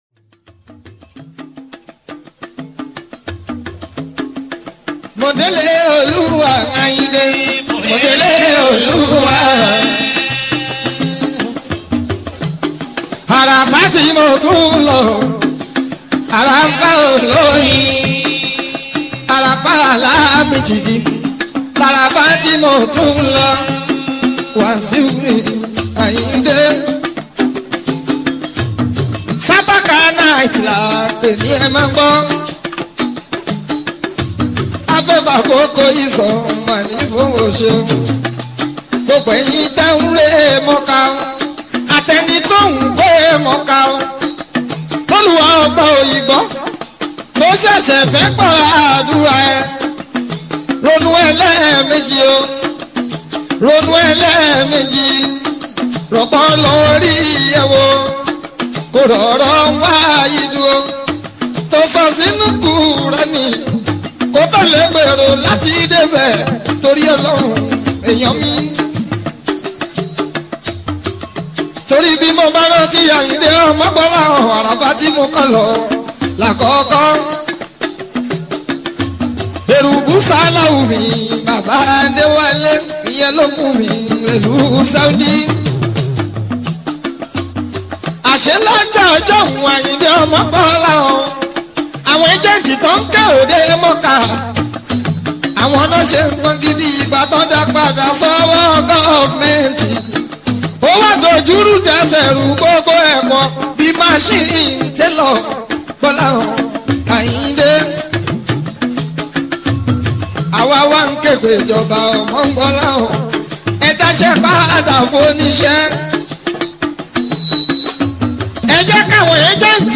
Nigerian Yoruba Fuji track
be ready to dance to the beats